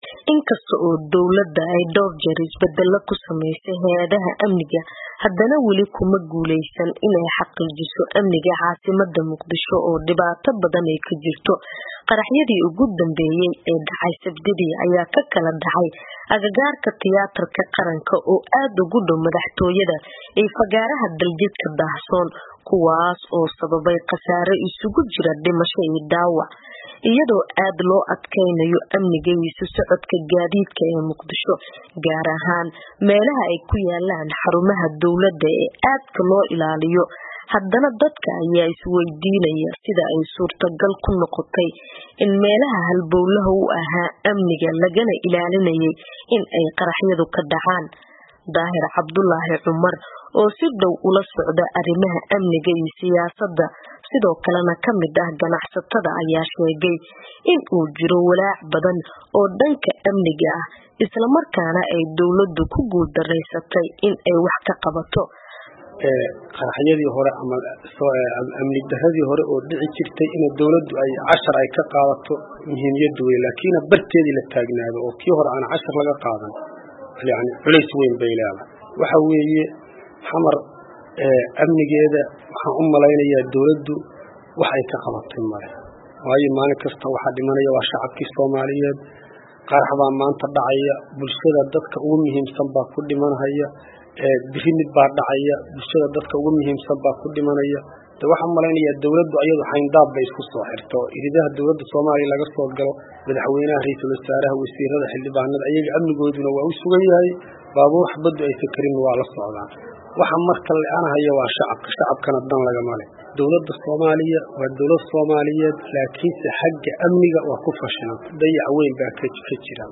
warbixintan oo ay ku eegeyso xaaladaha amniga ee Muqdisho iyo sida wax looga qaban karro ka soo dirtay Muqdisho.